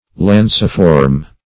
Search Result for " lanciform" : The Collaborative International Dictionary of English v.0.48: Lanciform \Lan"ci*form\, a. [Lance + -form: cf. F. lanciforme.]